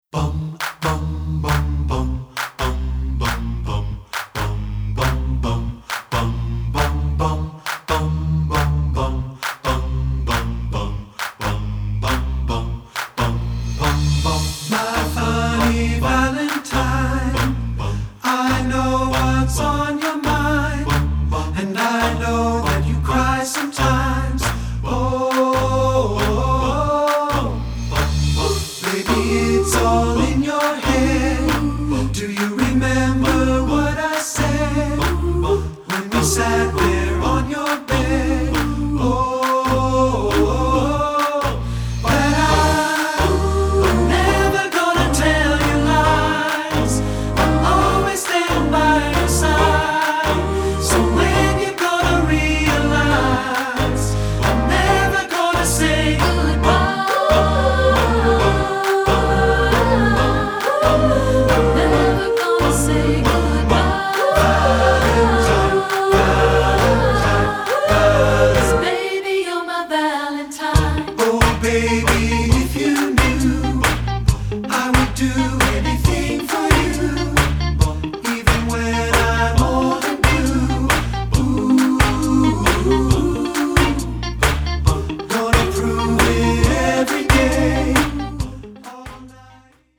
Choral Early 2000's Pop
Set either accompanied or a cappella
mixed voiced setting
SATB